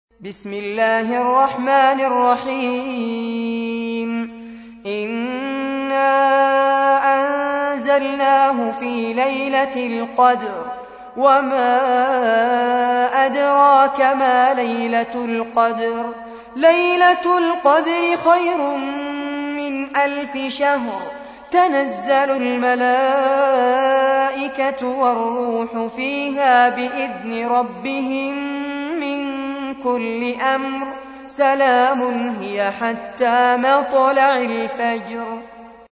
تحميل : 97. سورة القدر / القارئ فارس عباد / القرآن الكريم / موقع يا حسين